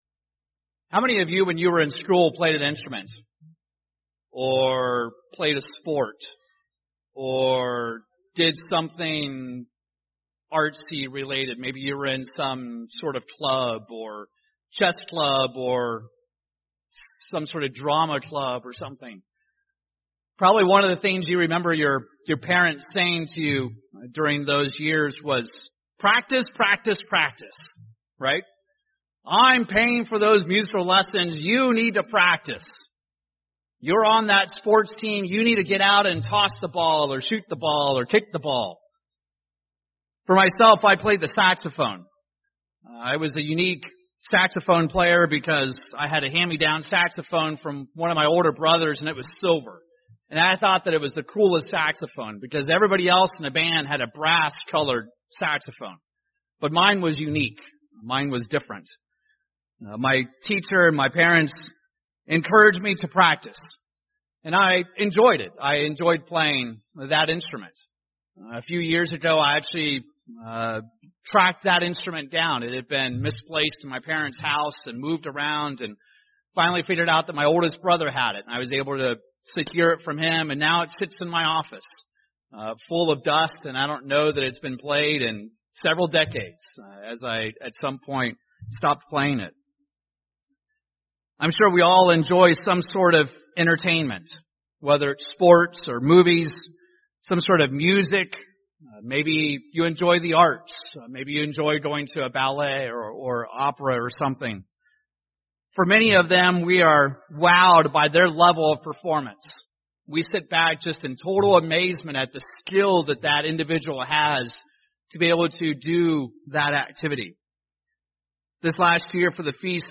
How diligent are you in your calling? In this sermon, we explore the concept of "Deliberate Practice" and how you can be a successful overcomer!
Given in Tulsa, OK